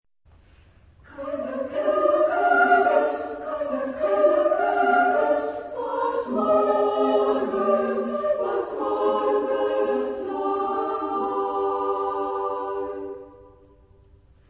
Genre-Style-Forme : Profane ; Lied
Type de choeur : SAA (div)  (3 voix égales de femmes )